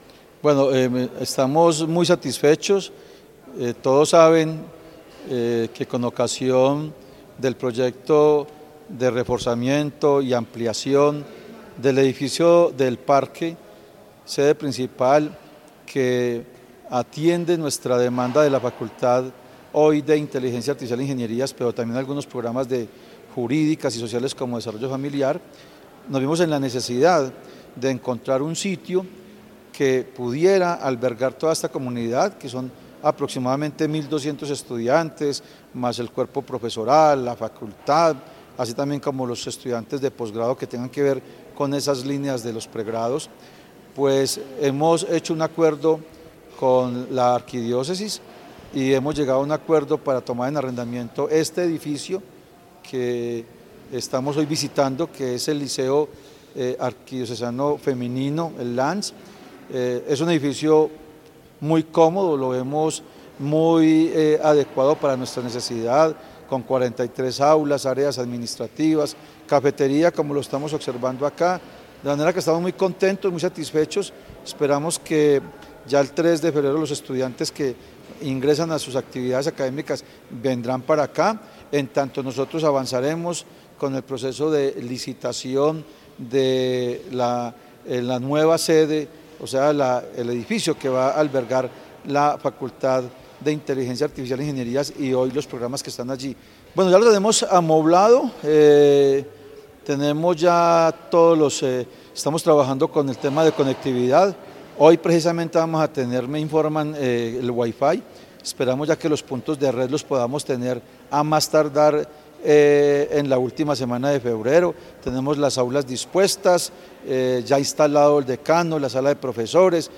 Audio Rector Universidad de Caldas, Fabio Hernando Arias Orozco.